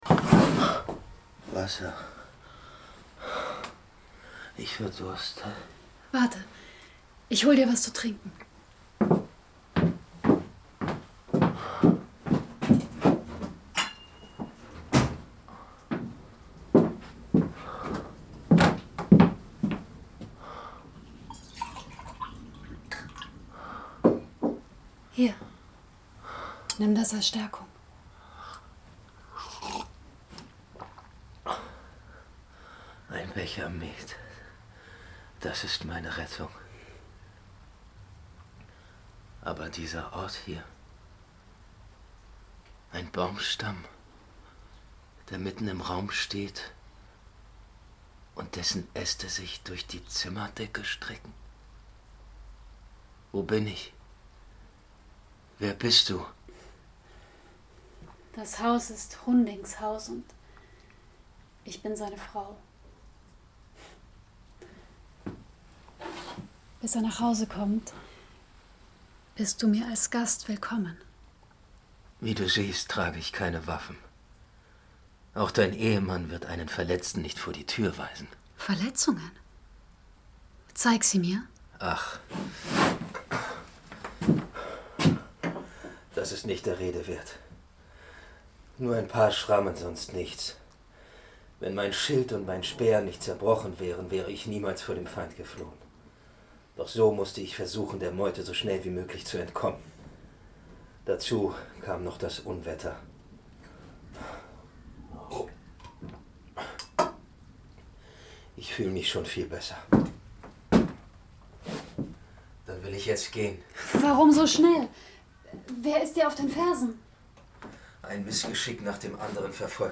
Die Walküre Hörspiel
Martina Gedeck , Bibiana Beglau , Bernhard Schütz (Sprecher)